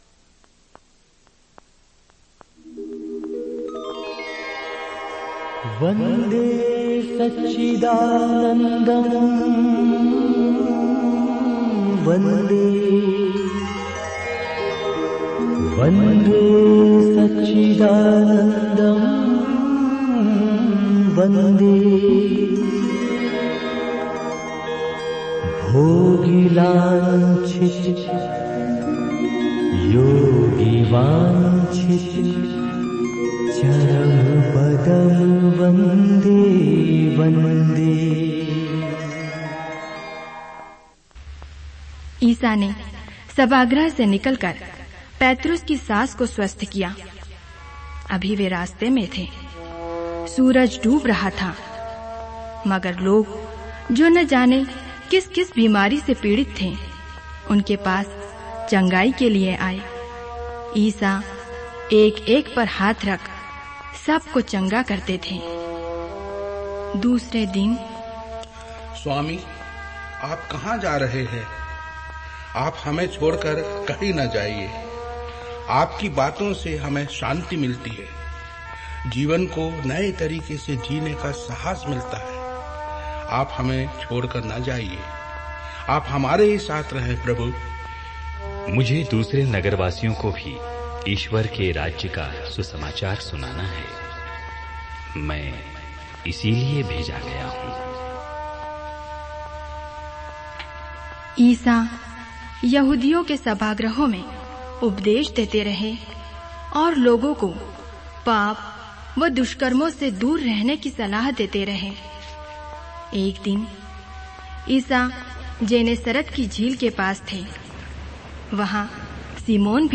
Bible Dramas